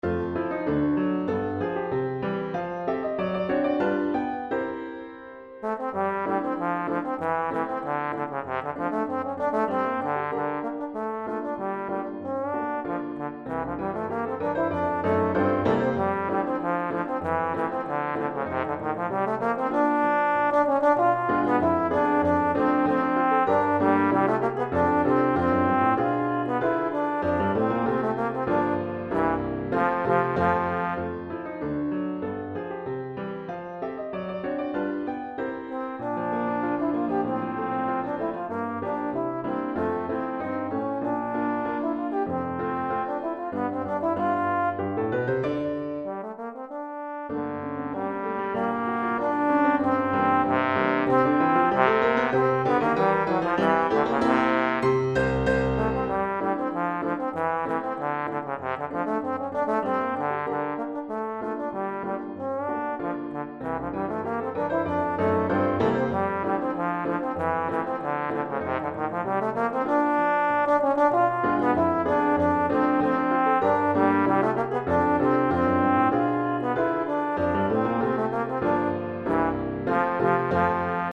Trombone et Piano